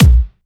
VFH2 128BPM Pitchford Kick.wav